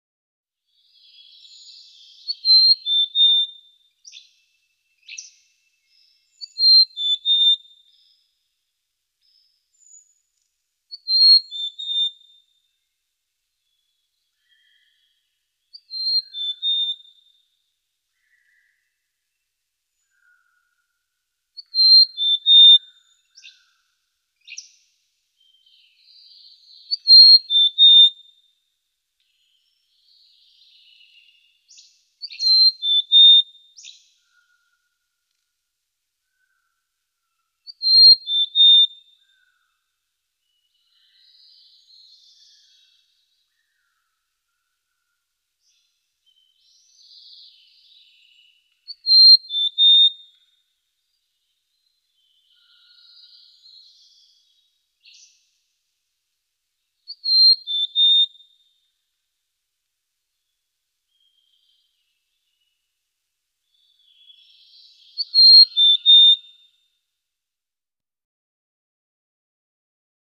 Quail, Mountain Chirps. Melodious Quail Chirps In Foreground Along With Other Bird Calls In Background. Medium Perspective.